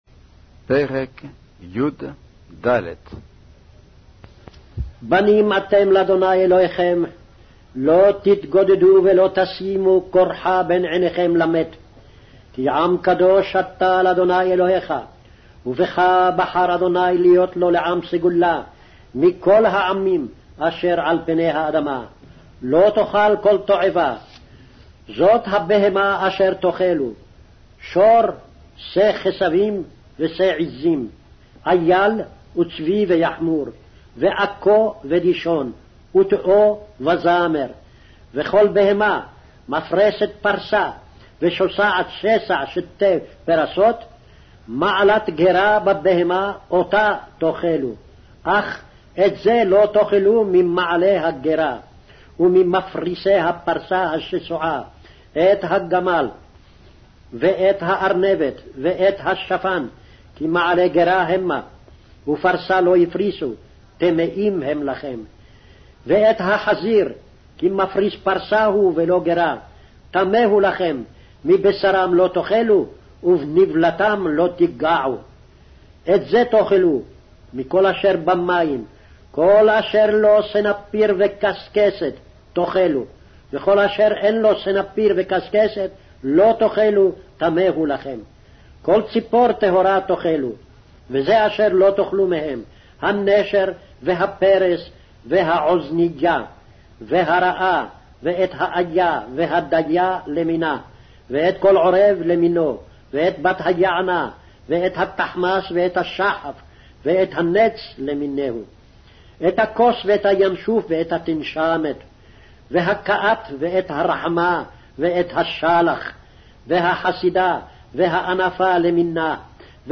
Hebrew Audio Bible - Deuteronomy 25 in Orv bible version